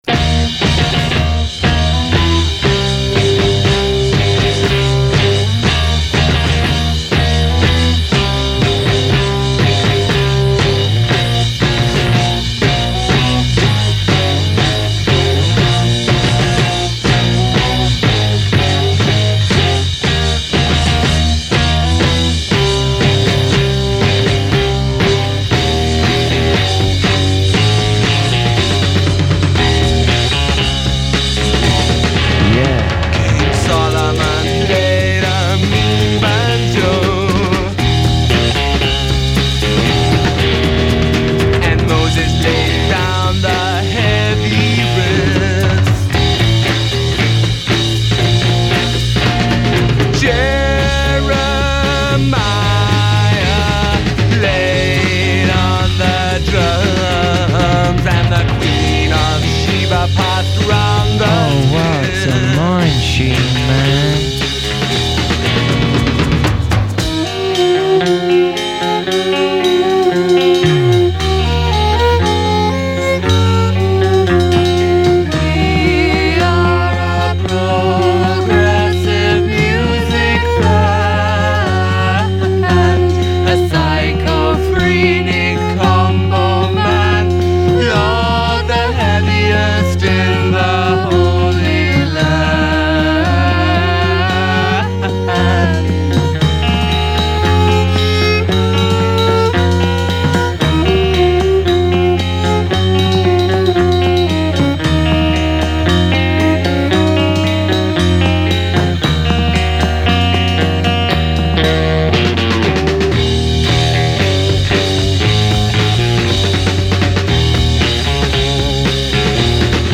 flashes of Prog, but a whole lot of tomfoolery as well.